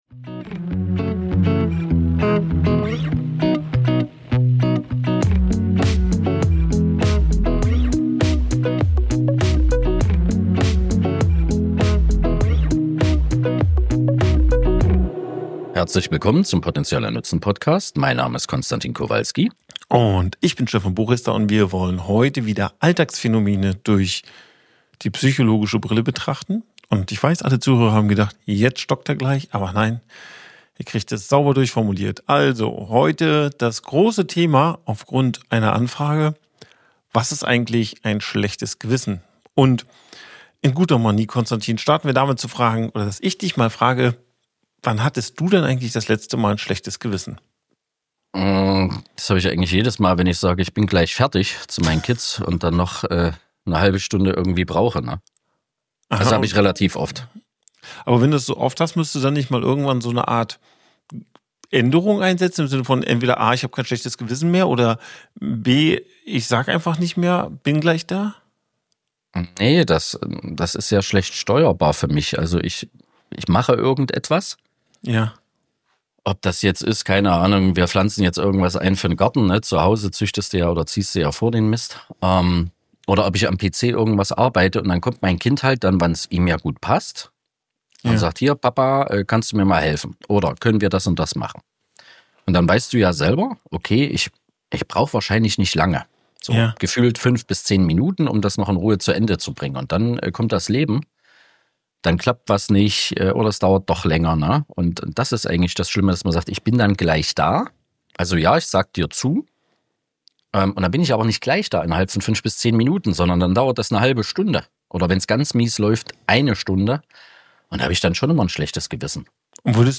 auf humorvolle und tiefgründige Weise